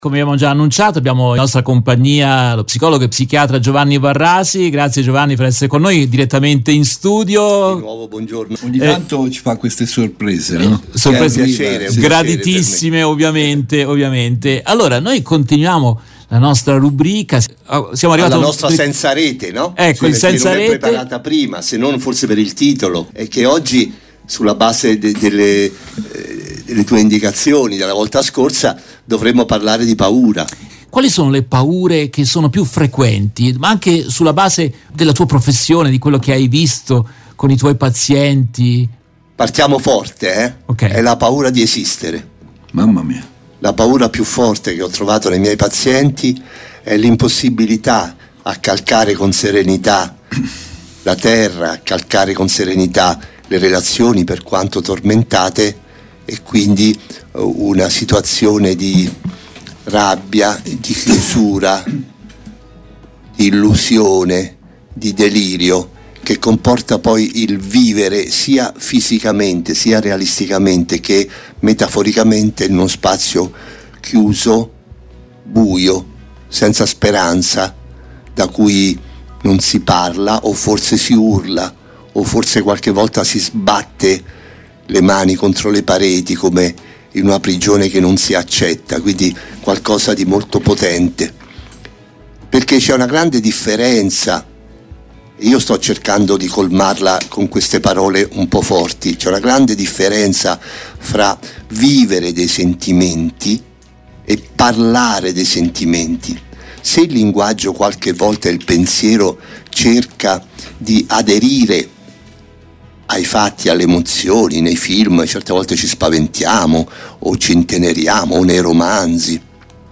psicologo e psichiatra, risponde ad alcuni temi posti da ascoltatori e dalla redazione. In questa trasmissione si concentra sul tema delle paure.